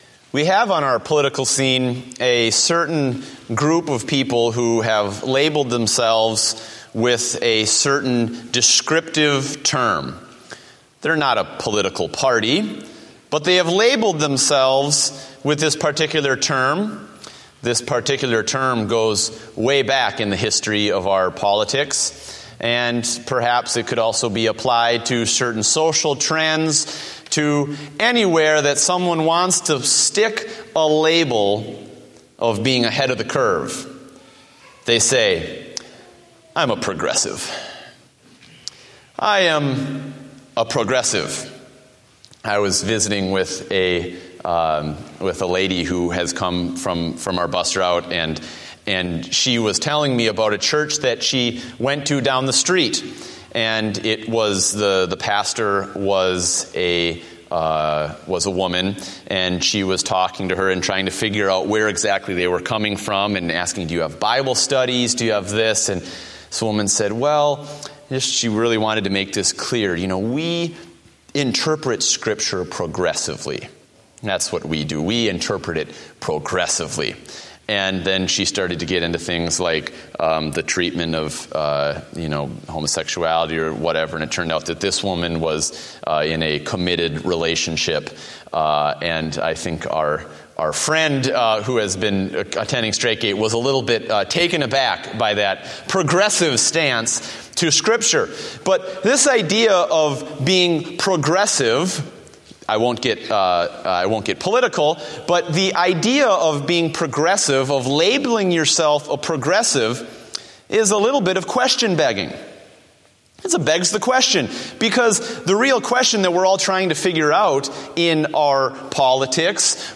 Date: April 6, 2014 (Evening Service)